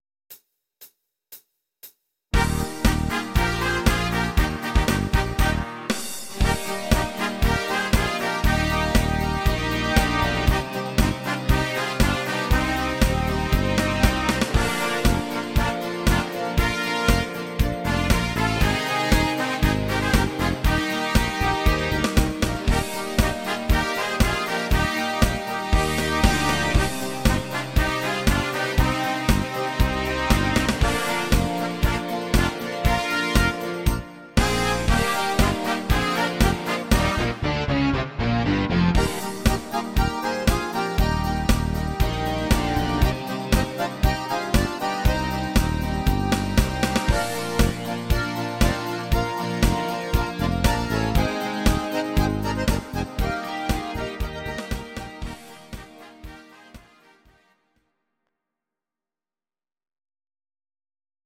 Audio Recordings based on Midi-files
German, Volkstï¿½mlich